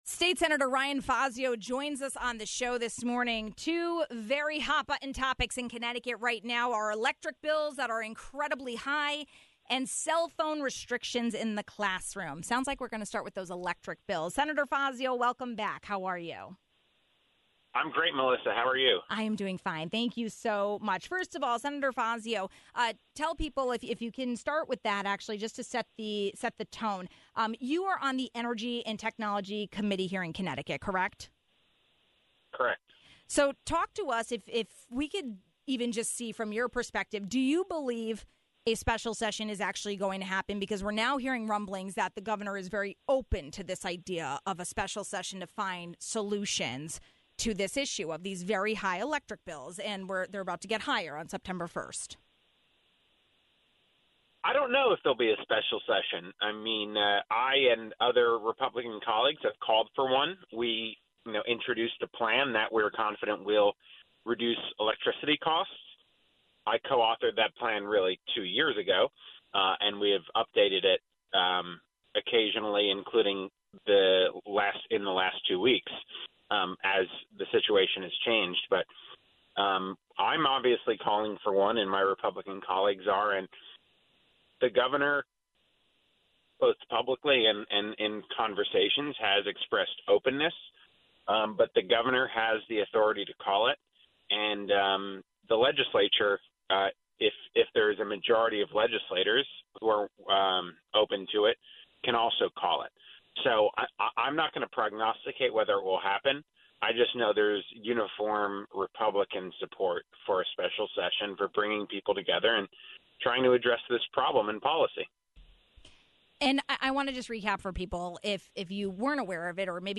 Two hot button topics right now in CT: high electric bills and restrictions on cell phones in public schools. Senator Ryan Fazio representing the 36th District and Ranking Member of the Energy Committee spoke on both topics.